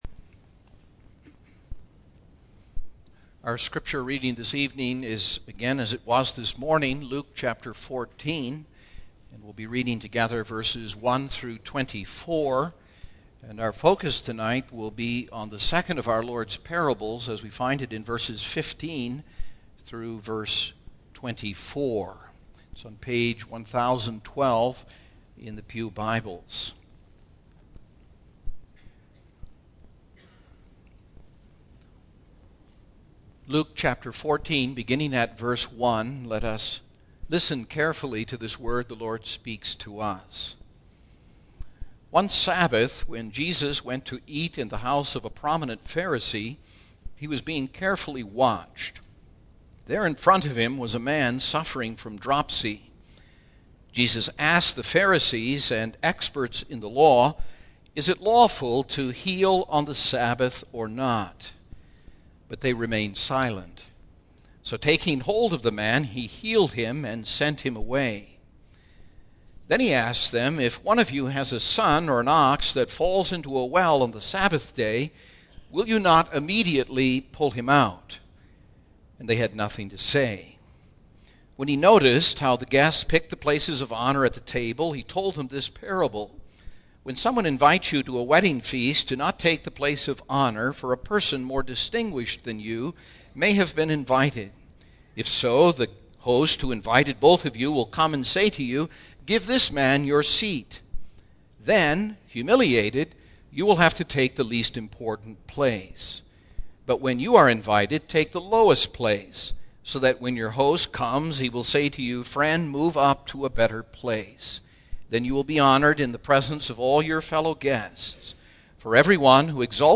Single Sermons Passage: Luke 14:1-24 %todo_render% « Worthy Guests at the Lord’s Table?